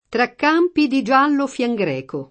fieno greco [fL$no gr$ko] o fienogreco [id.] s. m. (bot.) — anche fien greco [fLHj gr$ko] o fiengreco [id.]: sego per pastura Il fien greco [S%go per paSt2ra il fLHj gr$ko] (D’Annunzio); Tra campi di giallo fiengreco [